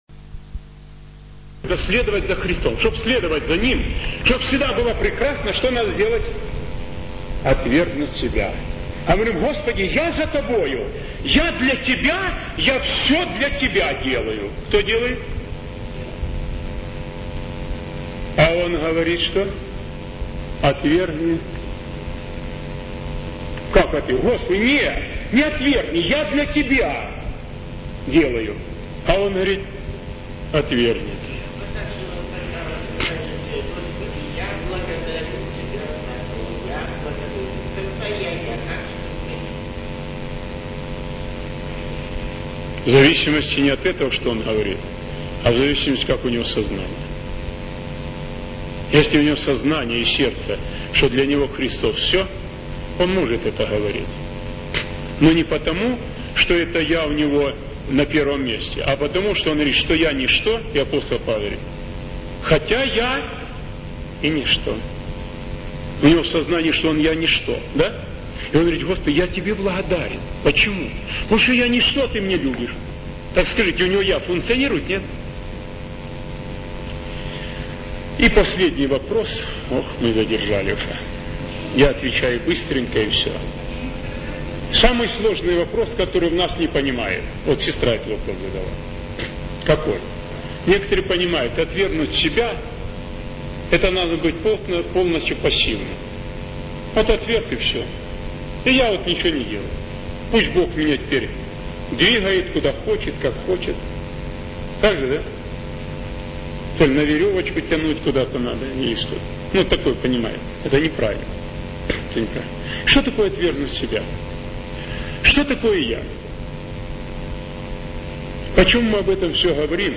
Аудио проповеди